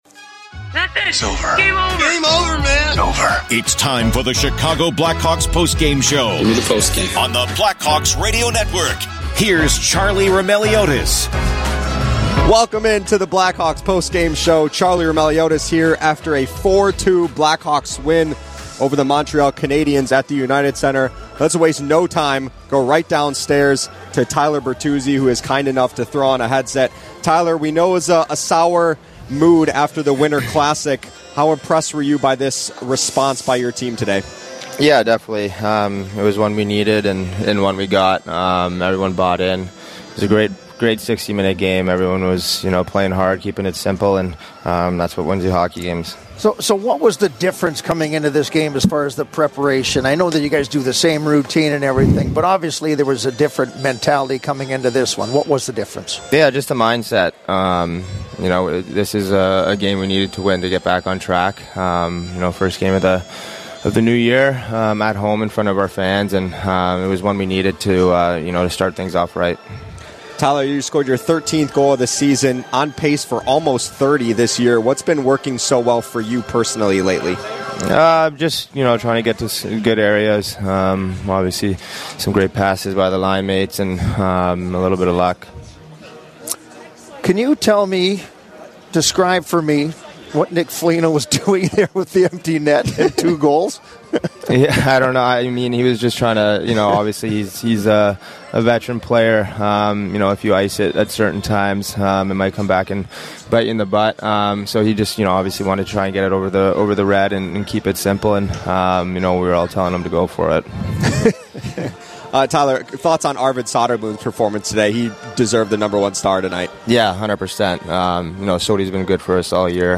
Left winger Tyler Bertuzzi joins the show to highlight what was clicking for the team tonight. Then, Troy Murray joins the discussion to talk about Arvid Söderblom’s outstanding job in goal and his improvement from last season.
Later in the show, hear postgame audio from captain Nick Foligno, newcomer Colton Dach, goaltender Arvid Söderblom, and interim coach Anders Sorensen.